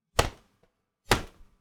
Cloth Slap Sound
household